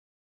Nationwide Co-Channel 70cm Repeater